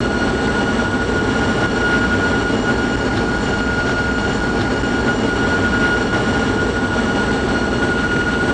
cfmlowspool-wingfwd.wav